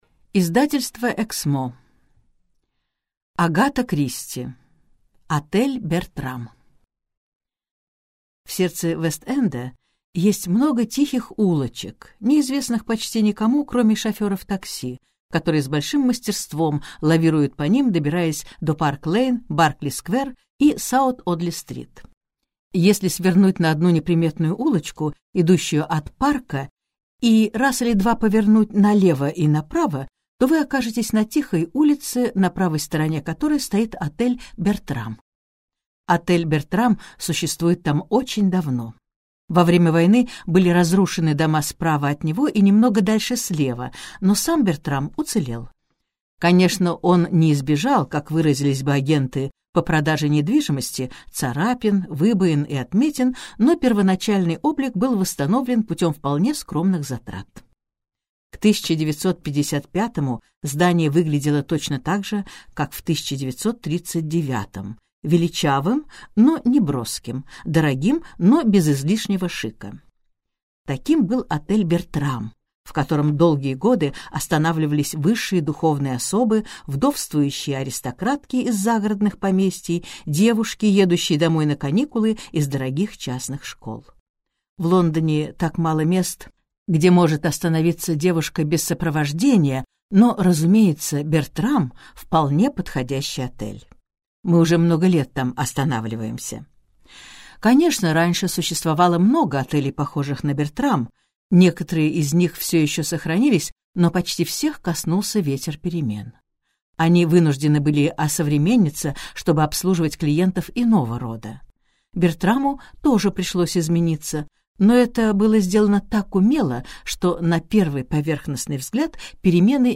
Аудиокнига Отель «Бертрам» - купить, скачать и слушать онлайн | КнигоПоиск